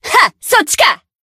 贡献 ） 分类:蔚蓝档案语音 协议:Copyright 您不可以覆盖此文件。
BA_V_Neru_Battle_Tacticalaction_1.ogg